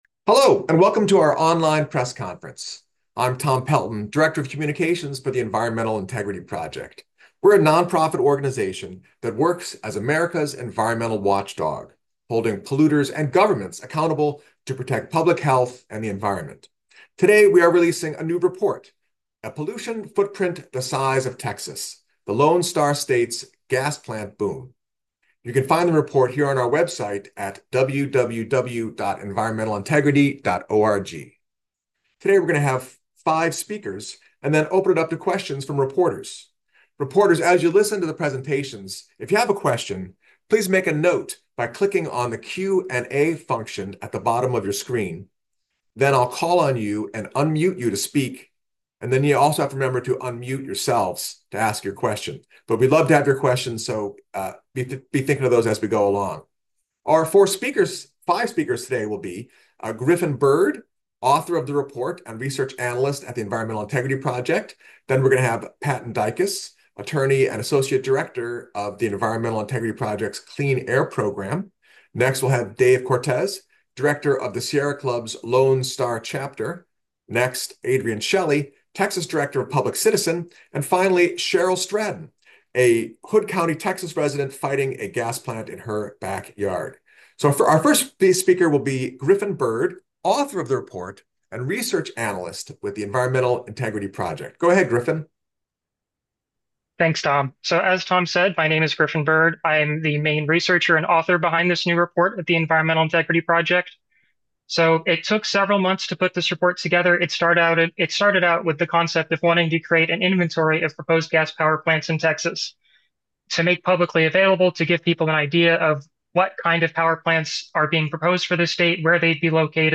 For audio of the online press conference, click here.